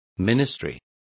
Complete with pronunciation of the translation of ministry.